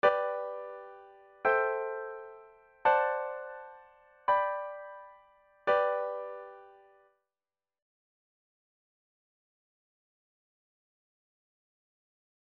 Chord Progression #1